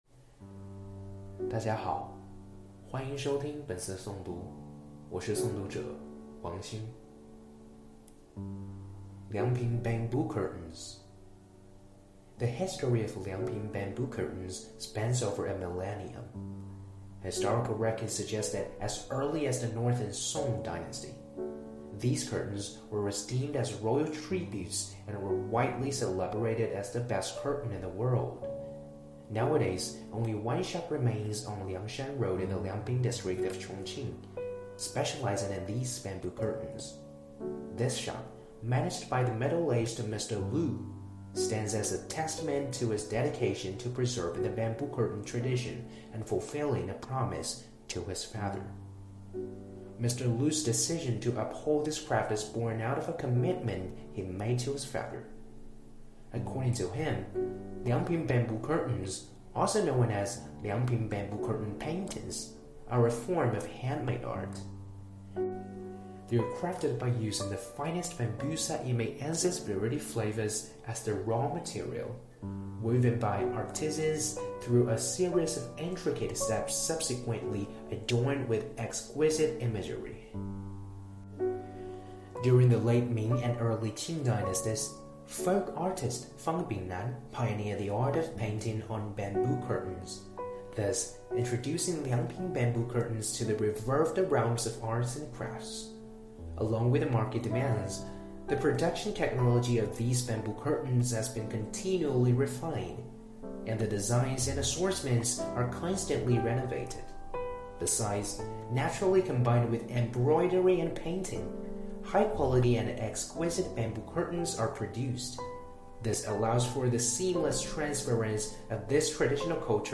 Editor's Note:  This article is produced in collaboration with the Chongqing Institute of Foreign Studies as part of a series of ongoing reports exploring the city's abundant resources in intangible cultural heritages.